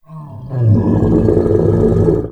MONSTER_Growl_Medium_21_mono.wav